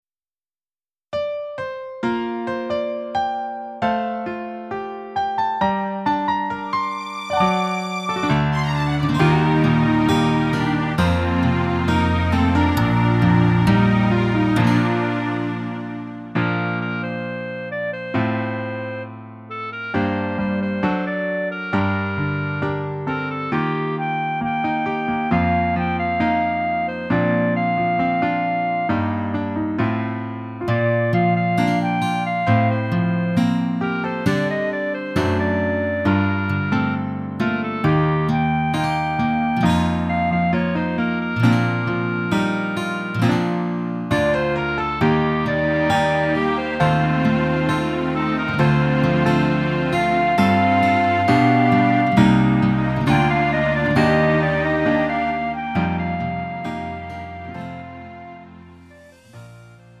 음정 원키 3:28
장르 가요 구분 Lite MR